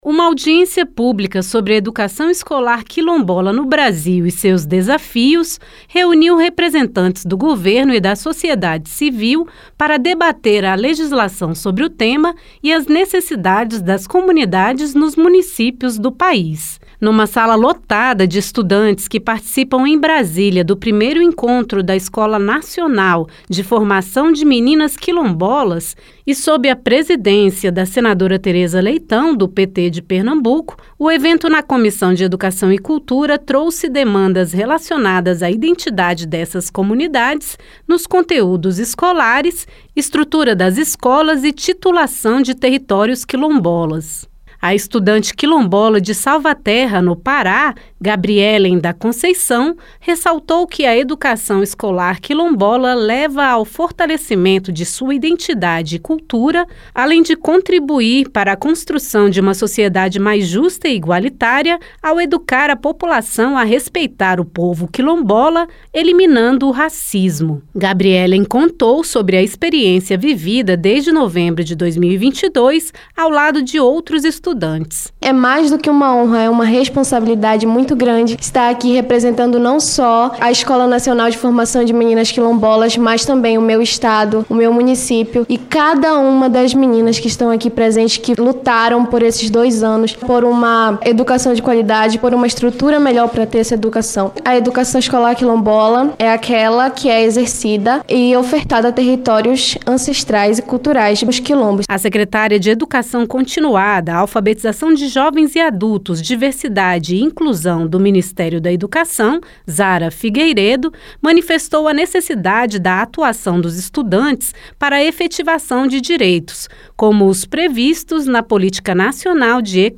No debate na Comissão de Educação e Cultura (CE), presidido pela senadora Teresa Leitão (PT-PE), a articulação política de estudantes e as diretrizes do governo federal para estados e municípios foram apontadas como essenciais na defesa dos interesses da população quilombola.